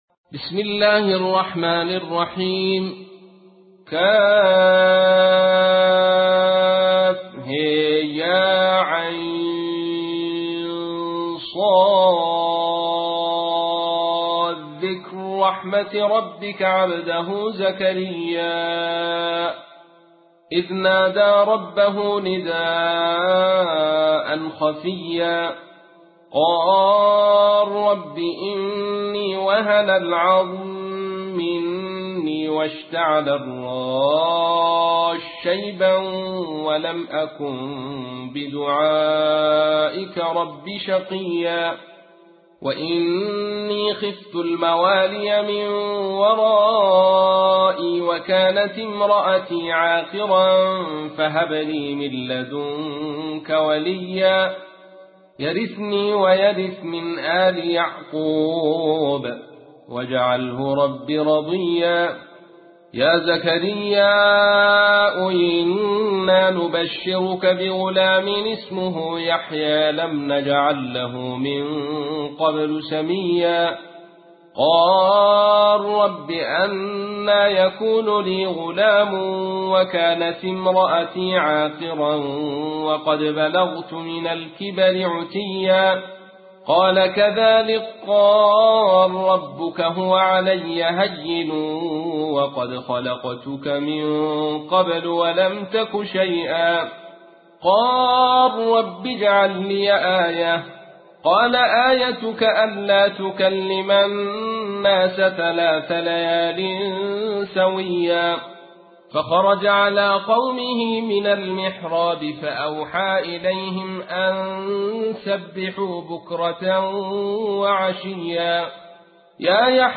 تحميل : 19. سورة مريم / القارئ عبد الرشيد صوفي / القرآن الكريم / موقع يا حسين